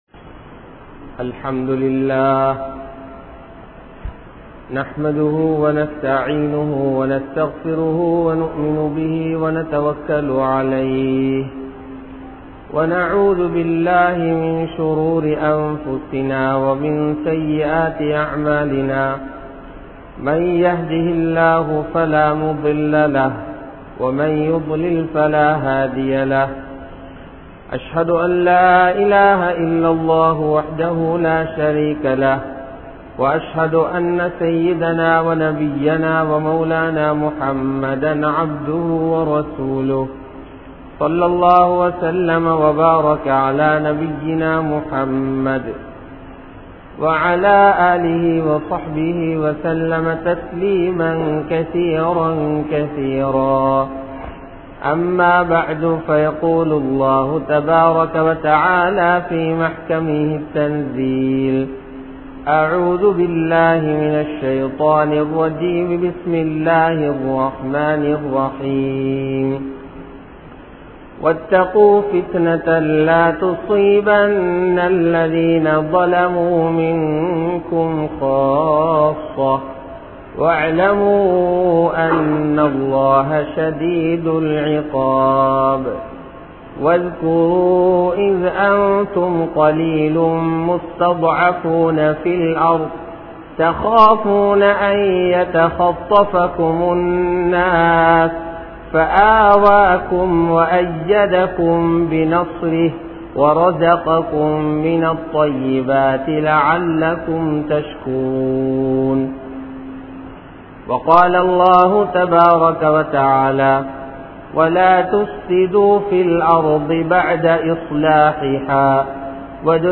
Aatsiyaalarhalai Theermanipathu ALLAH (ஆட்சியாளர்களை தீர்மாணிப்பது அல்லாஹ்) | Audio Bayans | All Ceylon Muslim Youth Community | Addalaichenai
Muhiyaddeen Grand Jumua Masjith